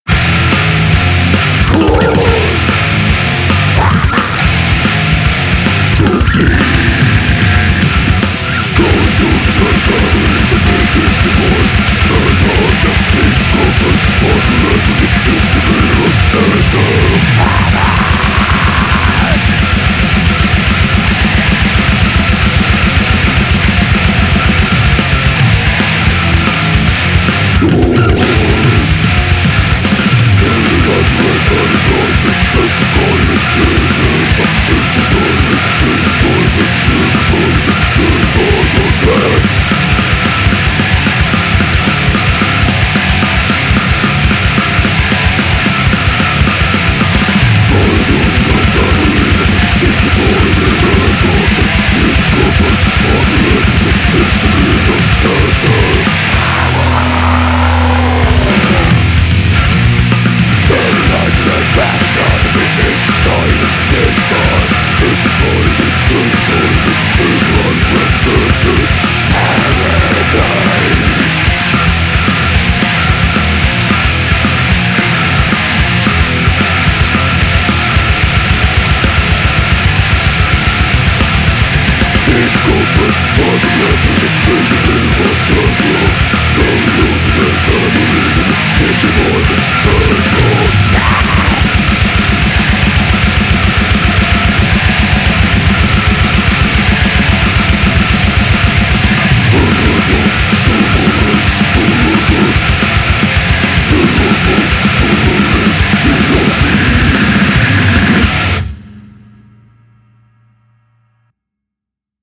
Ének